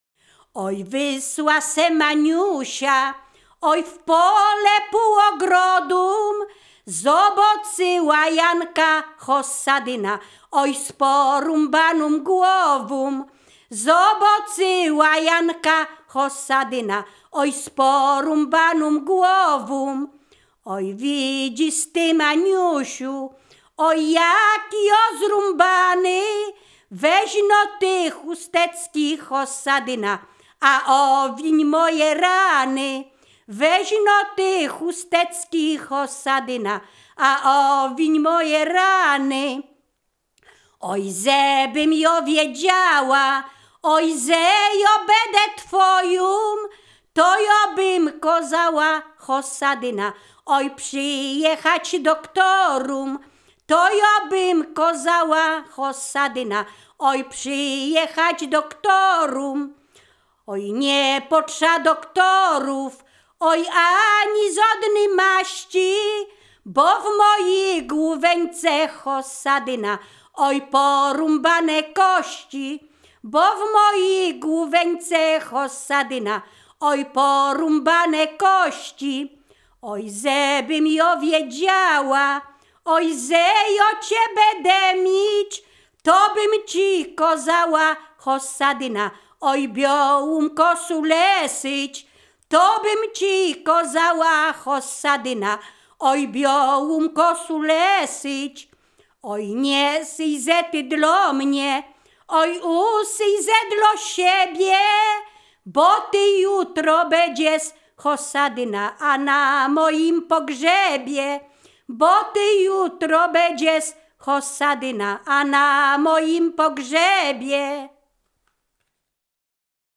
Ziemia Radomska
liryczne miłosne pieśni piękne żartobliwe